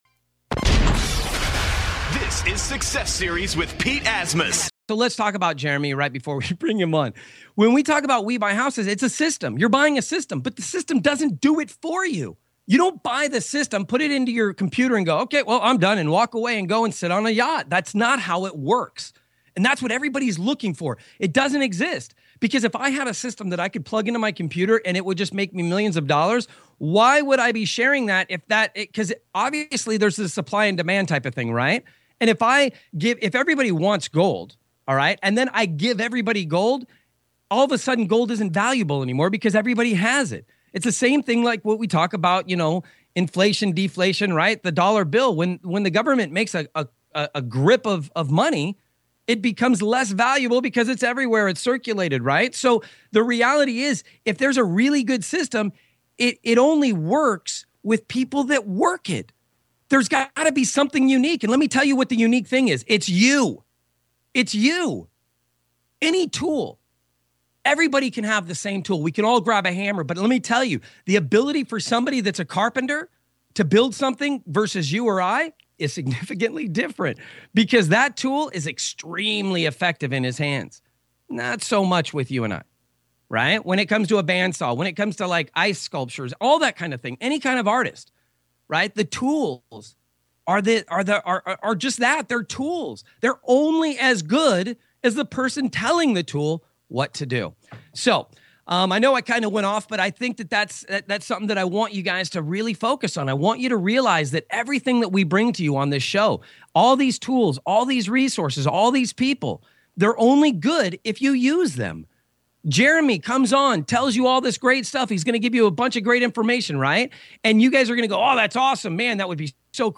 Here’s the full interview along with a link to Coast 2 Coast REIA.